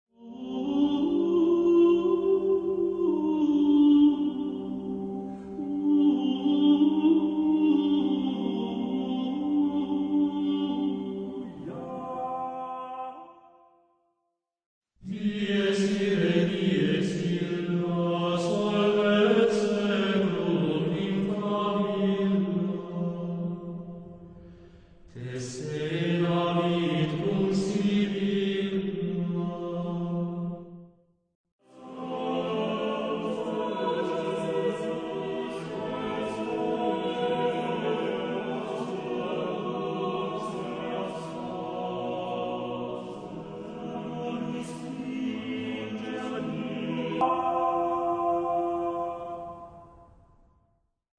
L'eleganza delle voci
E' intitolato Sederunt principes. Notiamo come alcune voci tendono a procedere a valori larghi, mentre altre si muovono con valori più brevi.
E' questo un tratto tipico delle prime forme di polifonia, in modo particolare di quella sorta nella scuola di Notre Dame.